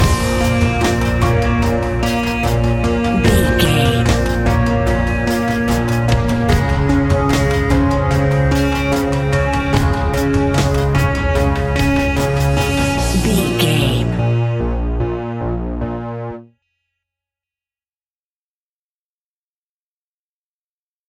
Aeolian/Minor
B♭
Slow
ominous
dark
haunting
eerie
synthesiser
electronic music
Horror Pads
Horror Synths